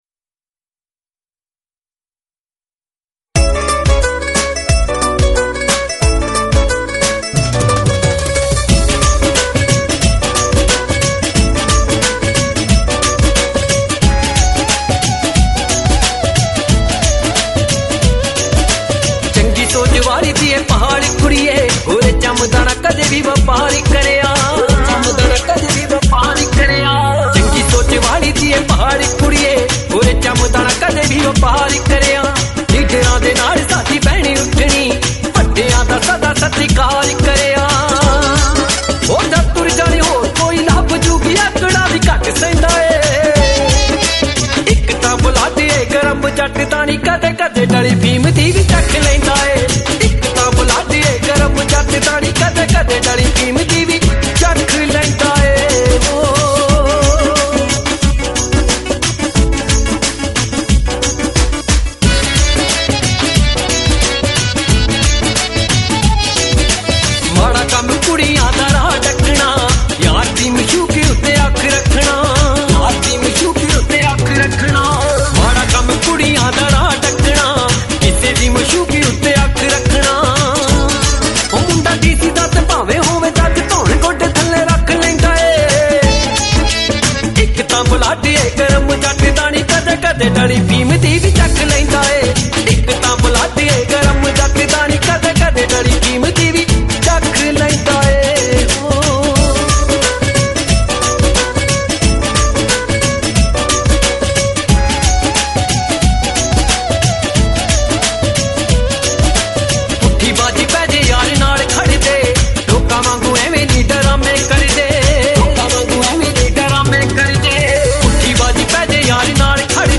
Genre Punjabi Old Song